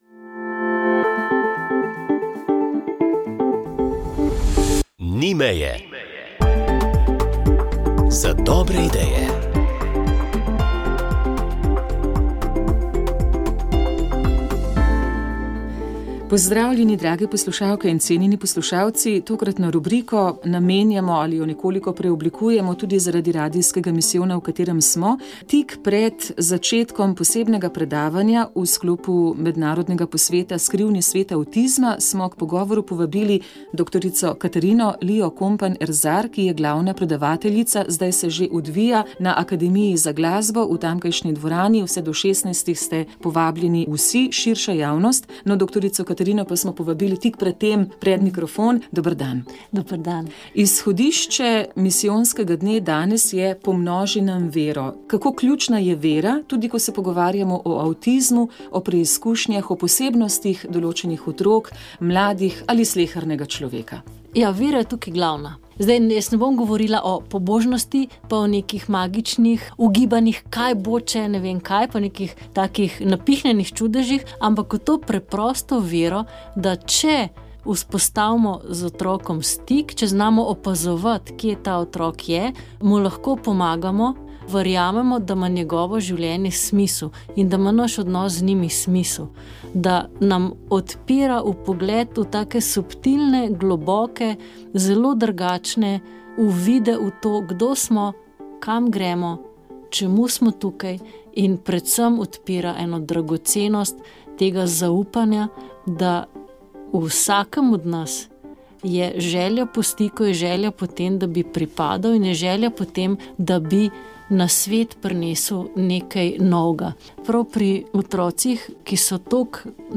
V Centru vesoljskih tehnologij Hermana Potočnika Noordunga v Vitanjah so odprli prvo igrificirano doživetje v Sloveniji. Pogovarjali smo se